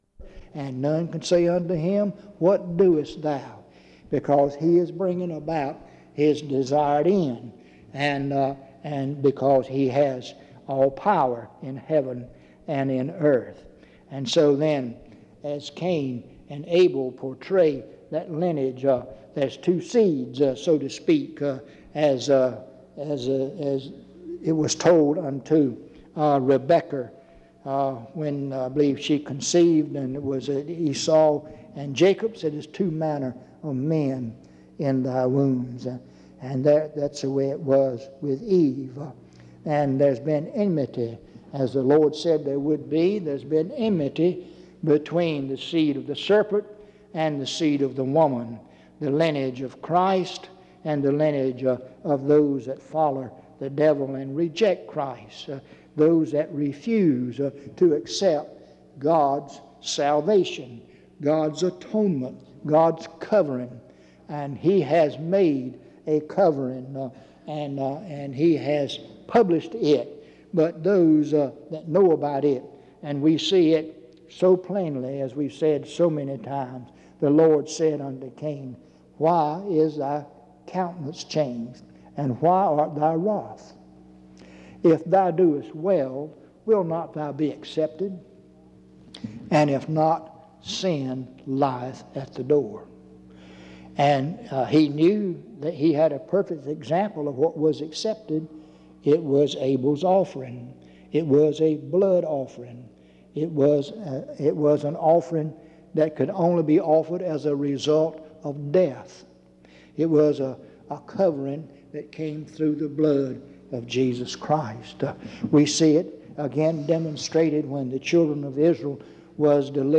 Lindsey Street Primitive Baptist Church audio recordings